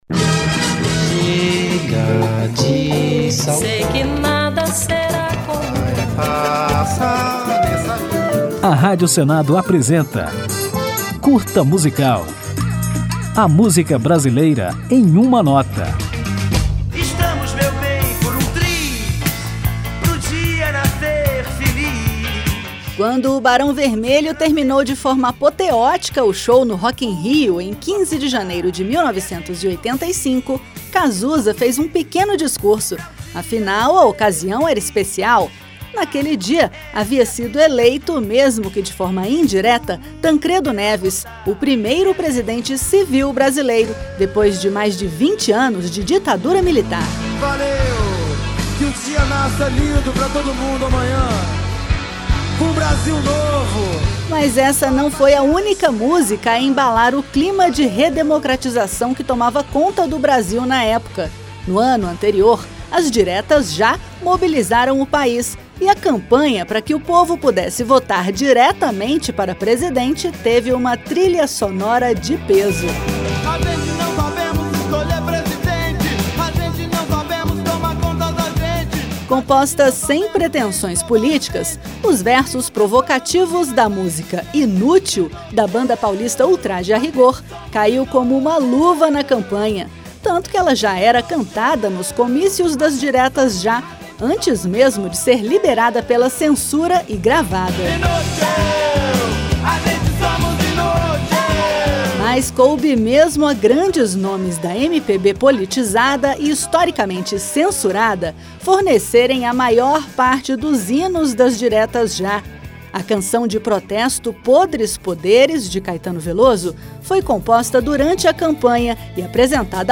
Muitas músicas embalaram os comícios das Diretas Já, movimento que eclodiu no Brasil em 1984. Neste Curta Musical você vai relembrar alguns desses "hinos" - como a canção Inútil, da banda Ultraje a Rigor - além de composições de Chico Buarque, Caetano Veloso e, é claro, Milton Nascimento, com a música Coração de Estudante, o maior de todos os hinos das Diretas Já, que toca ao final do programa.